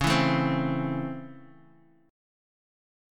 DbmM7#5 chord